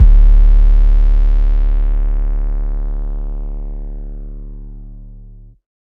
TS - 808 (3).wav